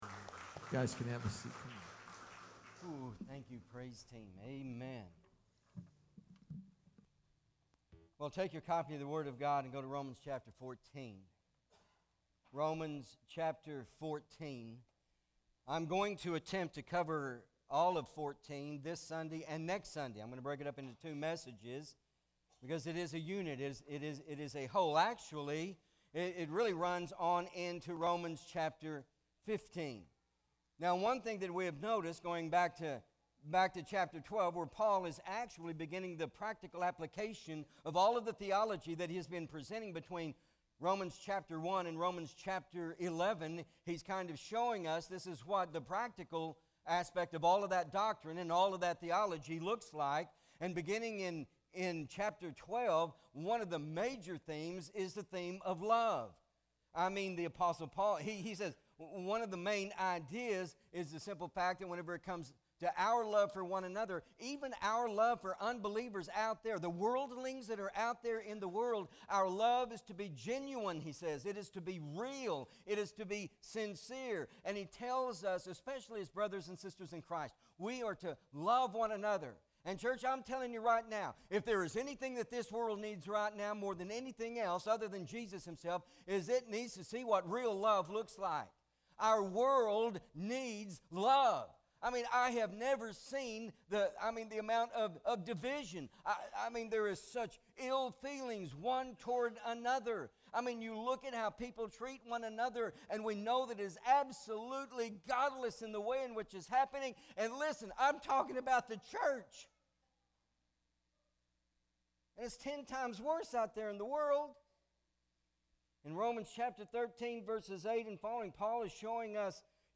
Romans Revealed-Love Doesn’t Pass Judgment MP3 SUBSCRIBE on iTunes(Podcast) Notes Sermons in this Series Romans 14:1-12 Not Ashamed!